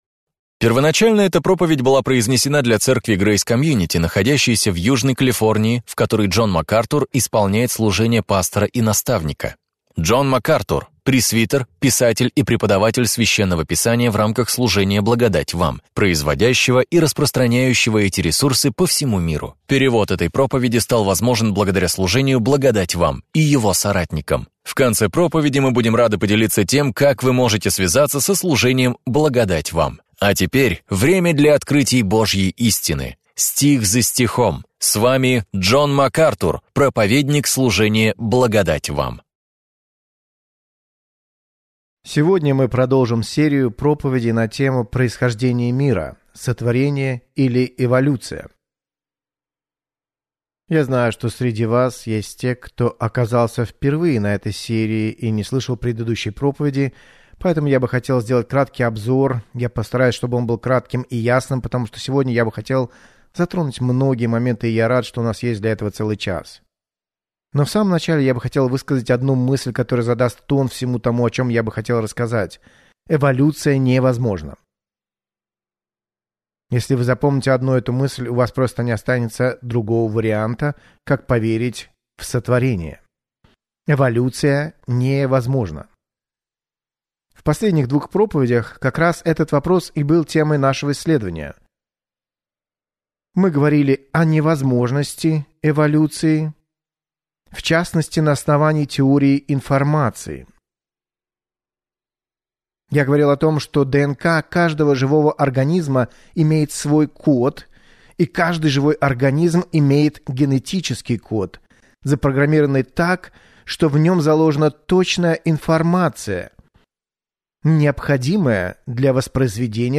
В своей проповеди «Битва за начало» Джон Макартур раскрывает суть этих споров